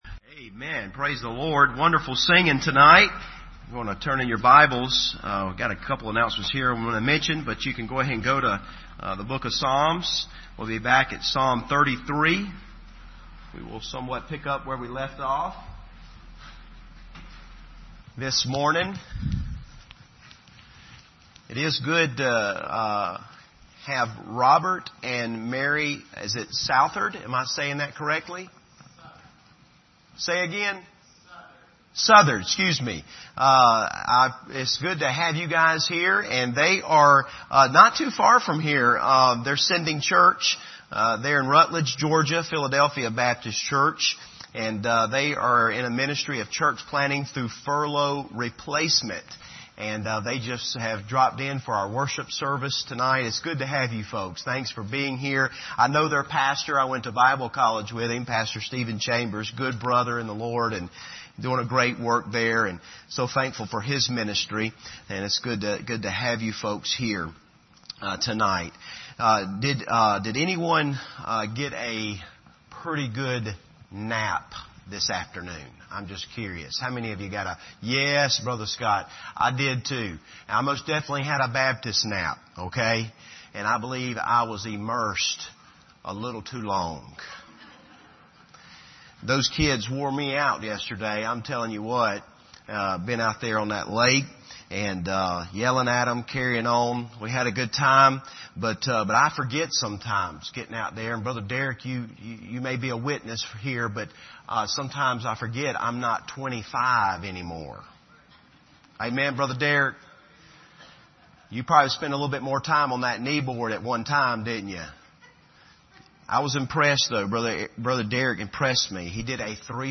Passage: Psalm 33:1-22 Service Type: Sunday Evening